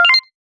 get_xp_01.wav